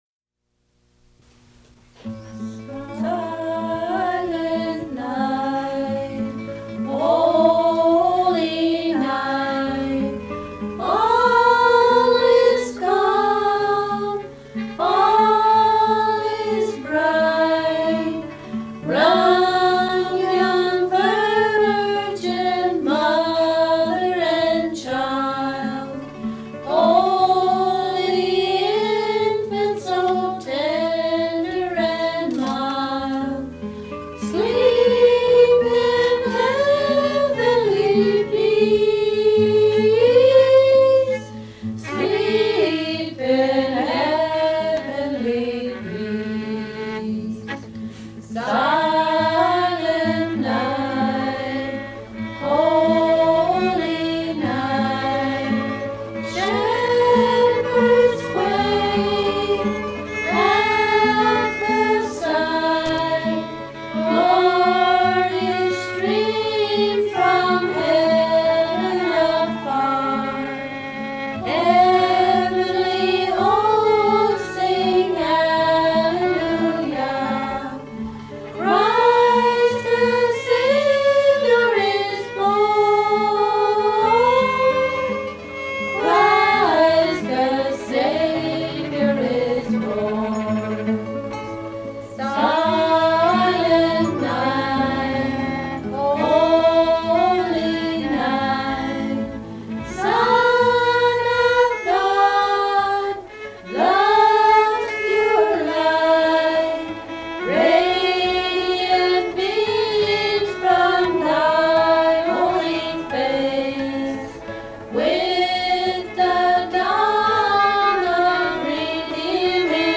Fait partie de Musical performance, interview with an Indigenous restaurant entrepreneur and Earth Arts Festival